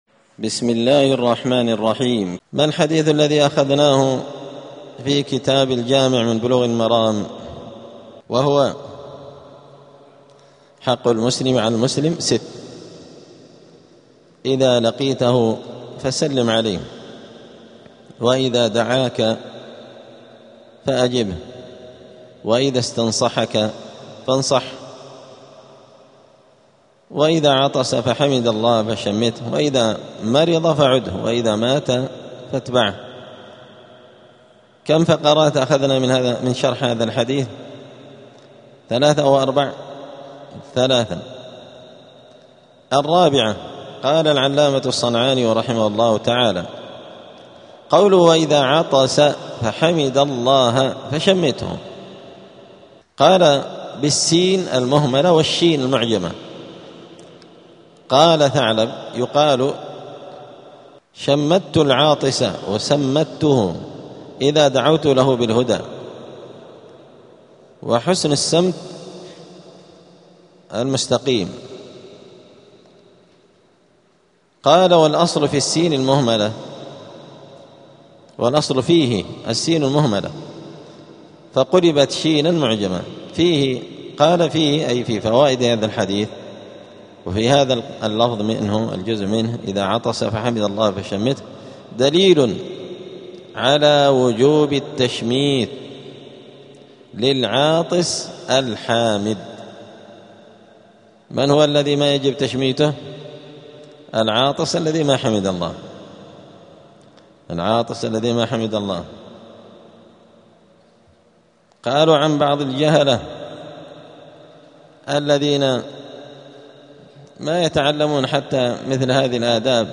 *الدرس الثاني (2) {باب حق المسلم على المسلم}*
دار الحديث السلفية بمسجد الفرقان قشن المهرة اليمن